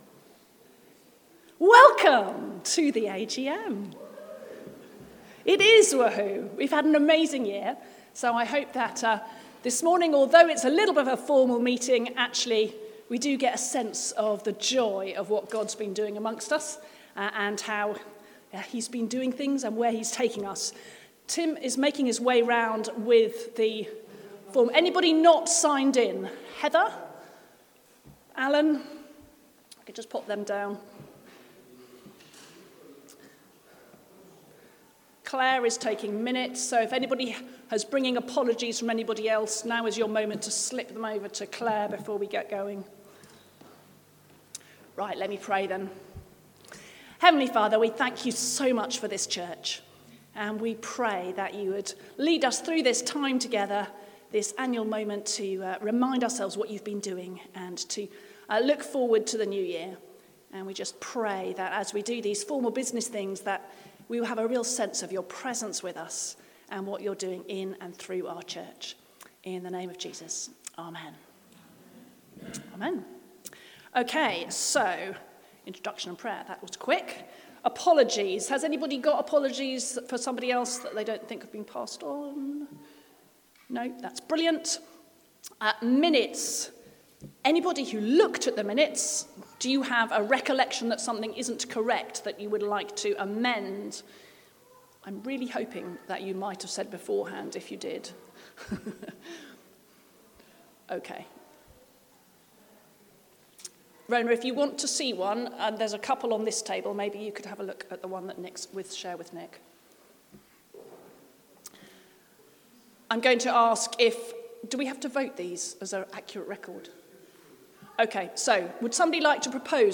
Annual General Meeting of All Saints Church, 2025